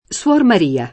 suora [SU0ra] (ant. suoro [SU0ro]) s. f. — tronc. davanti a pers. f.: suor Maria [